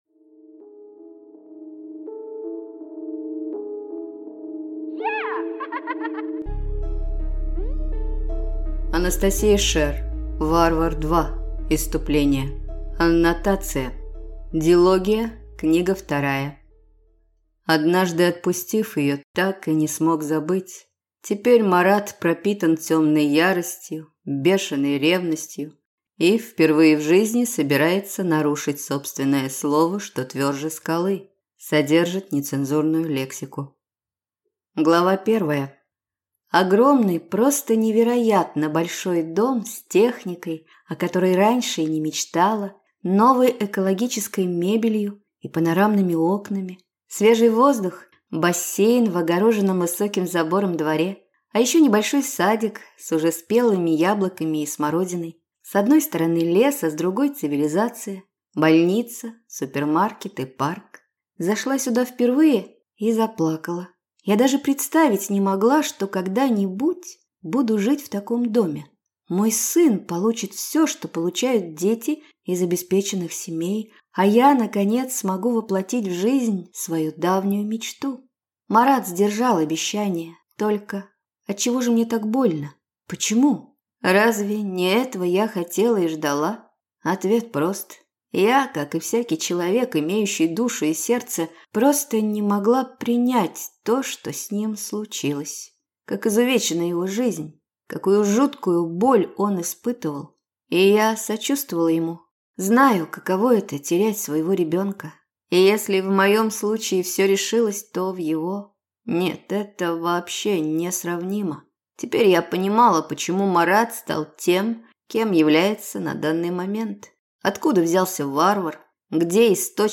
Аудиокнига Варвар 2. Исступление | Библиотека аудиокниг